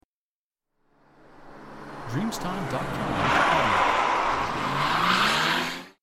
Auto-Rutschen